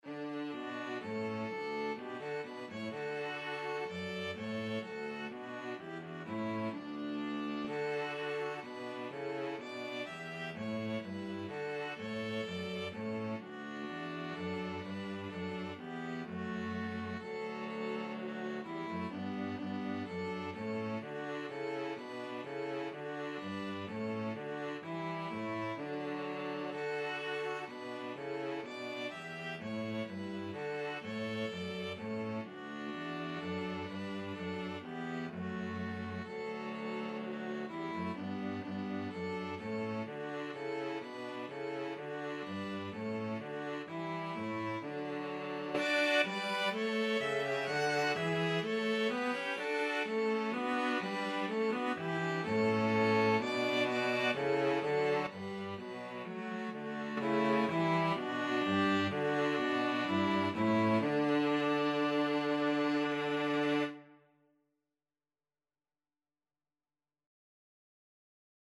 ViolinViolaCello
Allegro = 126 (View more music marked Allegro)